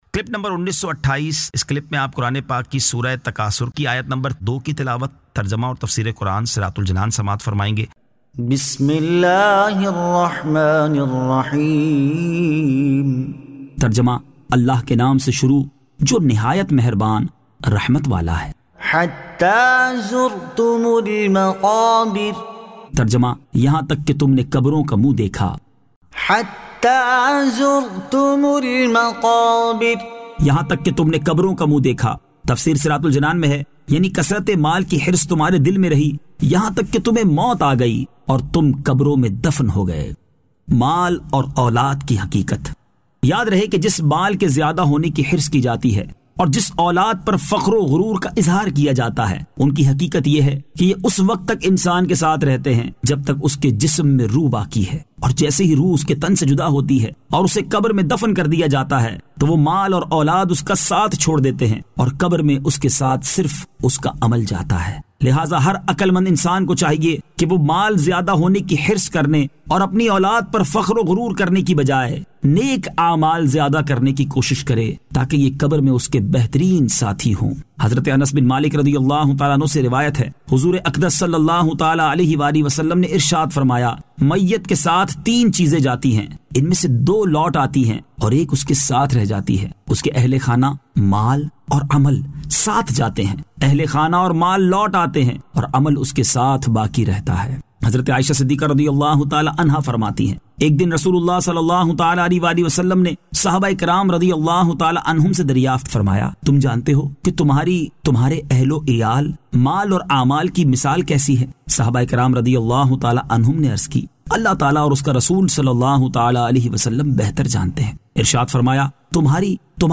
Surah At-Takathur 02 To 02 Tilawat , Tarjama , Tafseer
2025 MP3 MP4 MP4 Share سُوَّرۃُ التَّکَاثُرْ آیت 02 تا 02 تلاوت ، ترجمہ ، تفسیر ۔